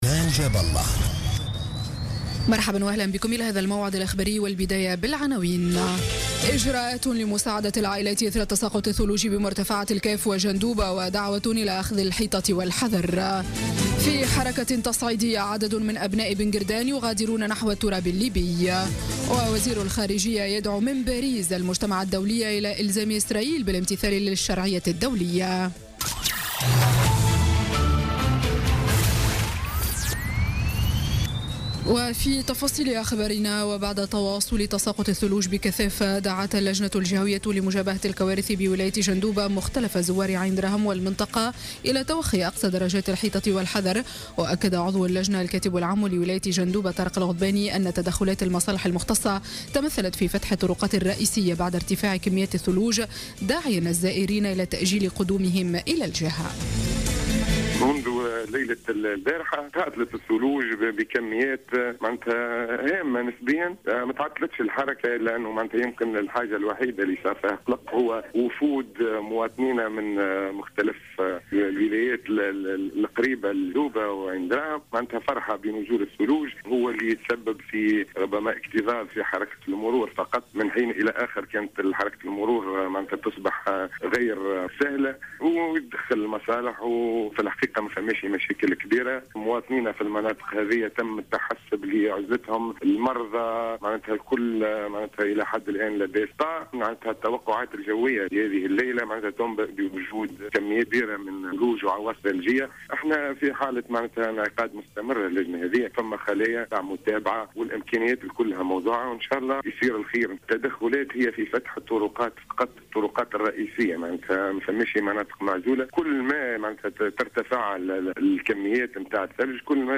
نشرة أخبار السابعة مساء ليوم الأحد 15 جانفي 2017